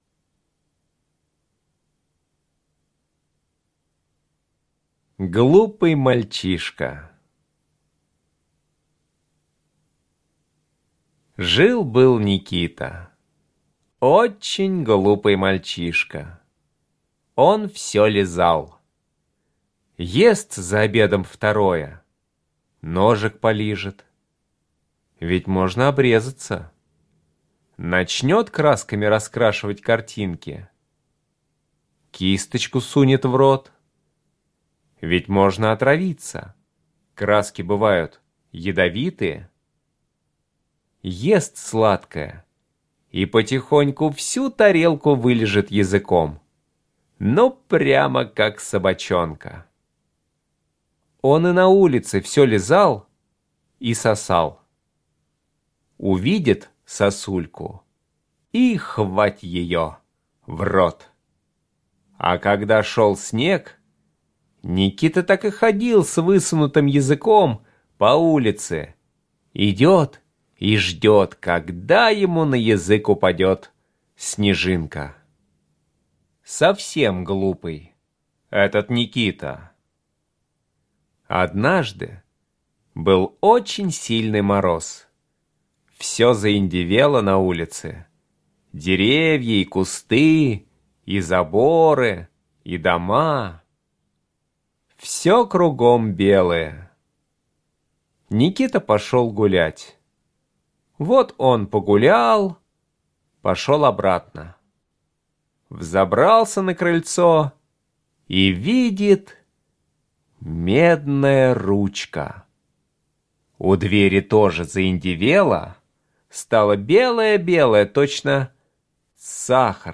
Аудиорассказ «Глупый мальчишка»